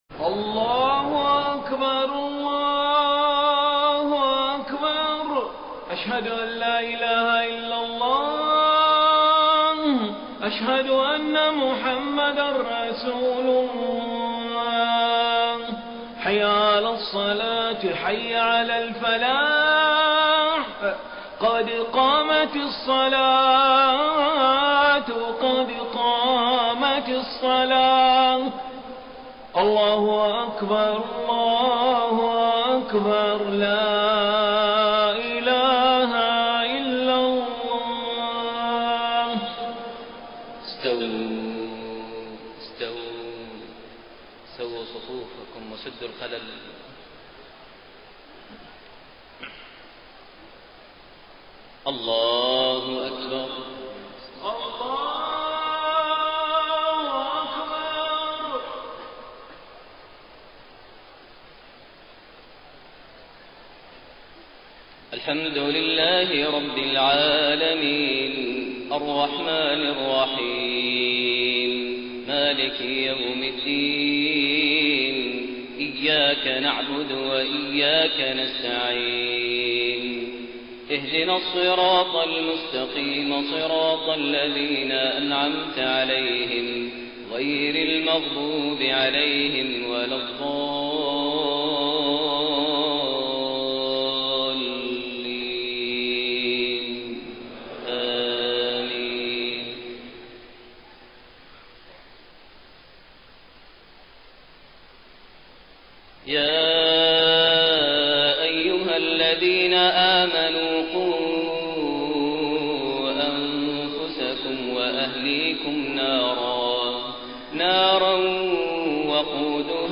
صلاة العشاء 17 ذو الحجة 1432هـ خواتيم سورة التحريم 6-12 > 1432 هـ > الفروض - تلاوات ماهر المعيقلي